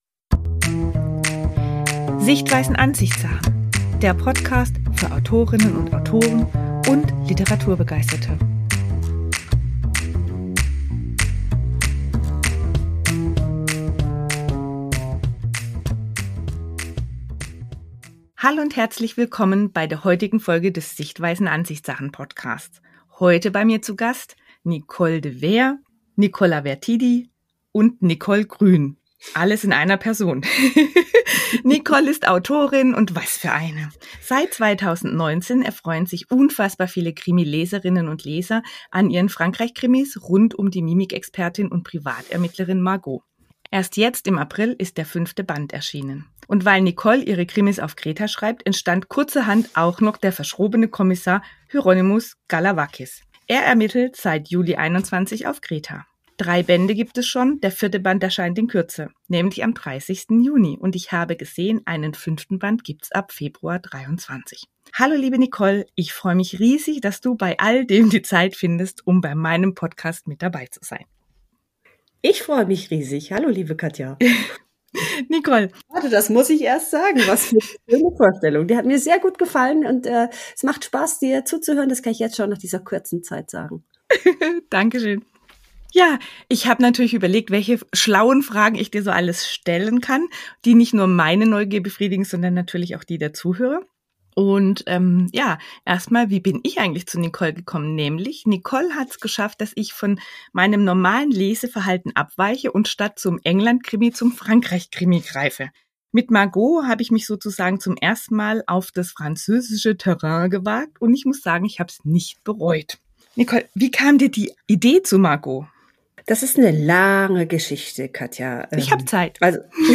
Autorinnengespräch